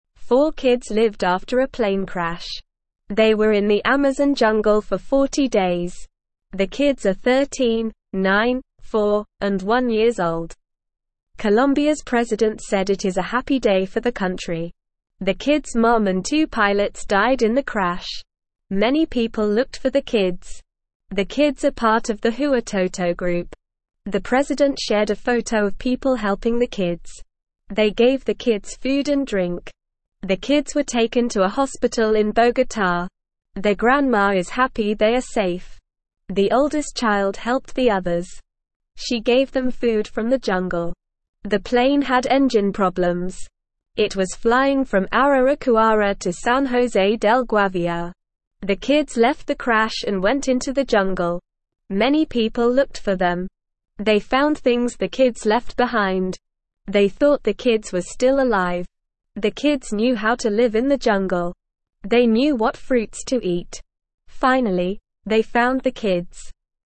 Normal
English-Newsroom-Beginner-NORMAL-Reading-Four-Kids-Survive-Jungle-Plane-Crash.mp3